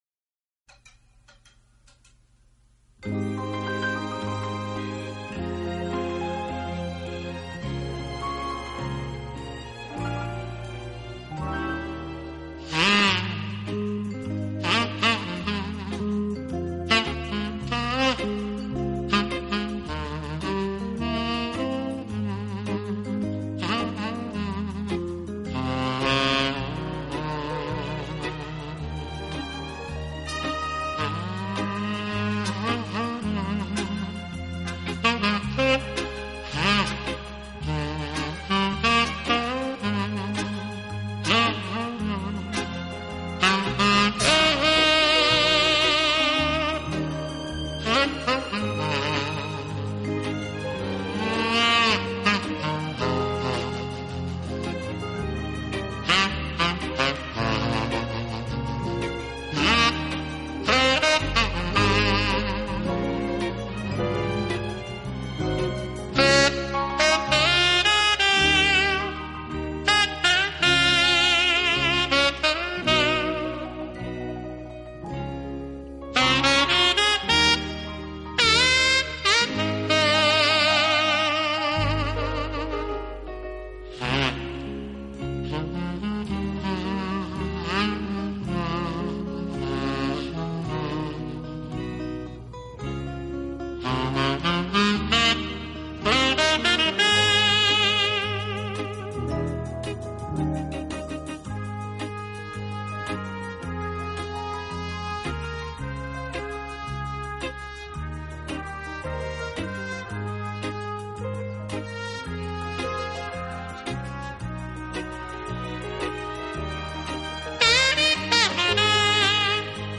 Genre....: Instrumental